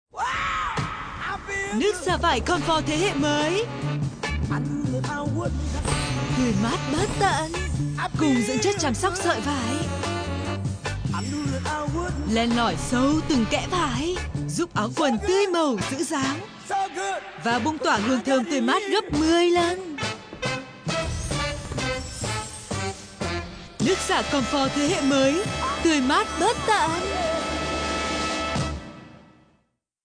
Promos
I am a skilled, multi-lingual voice actor with over 13+ years of experience, native to Vietnam and speaking Vietnamese as my primary language (North Accent).
Records from my home studio or on location in Ho Chi Minh City and other connected cities throughout Vietnam.